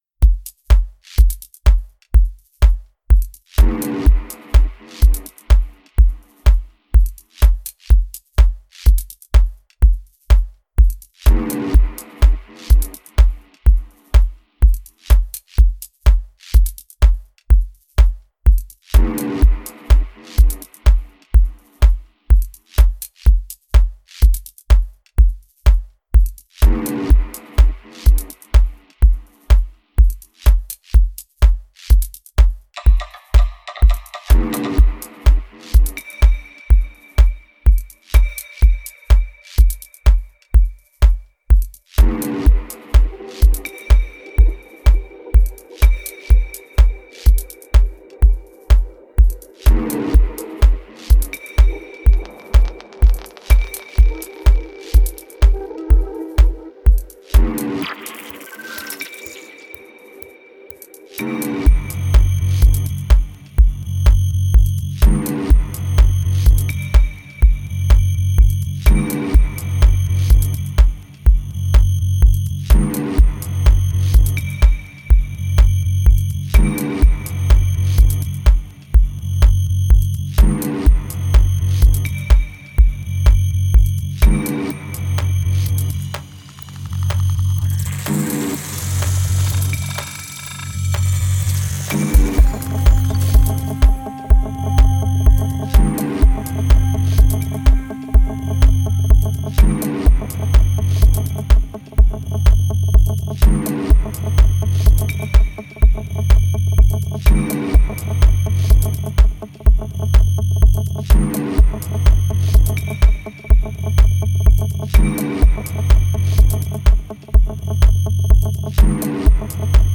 Minimal techno